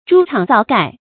朱轓皂蓋 注音： ㄓㄨ ㄈㄢ ㄗㄠˋ ㄍㄞˋ 讀音讀法： 意思解釋： 紅色的車障，黑色的車蓋。